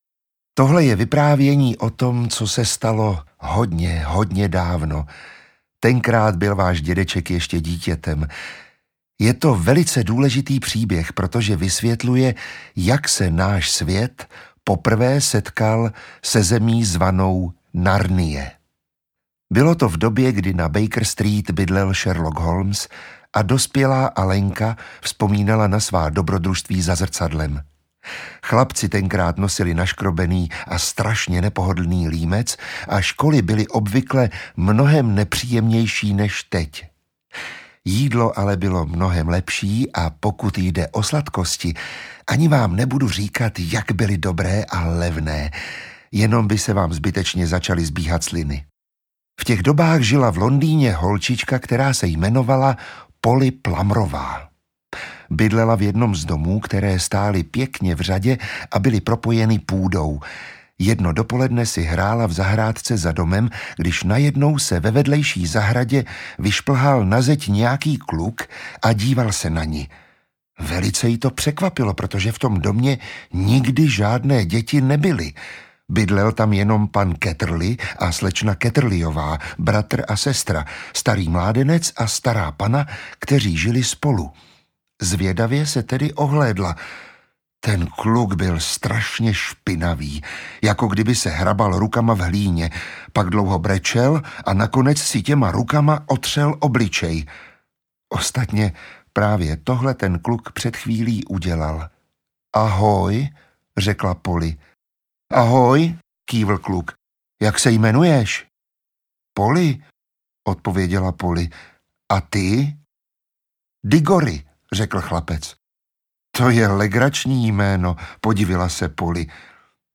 Letopisy Narnie 1 – Čarodějův synovec audiokniha
Ukázka z knihy
• InterpretMiroslav Táborský
letopisy-narnie-1-carodejuv-synovec-audiokniha